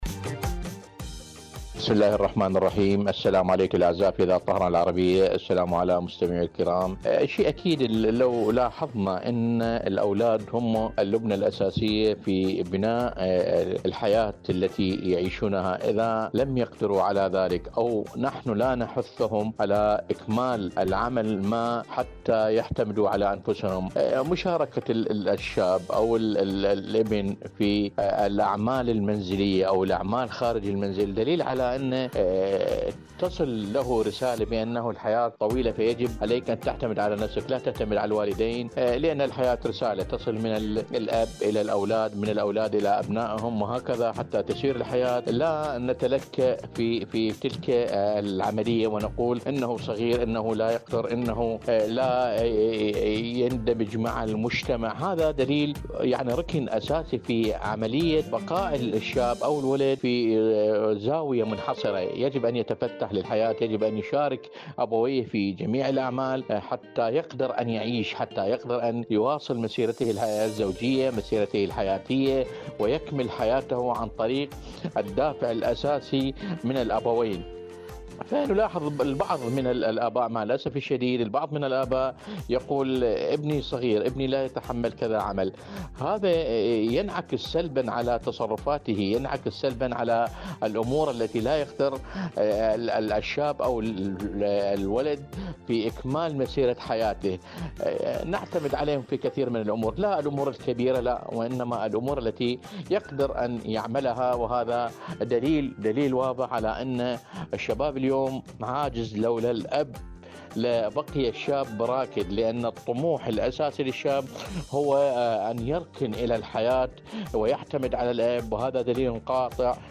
مشاركة صوتية
إذاعة طهران- معكم على الهواء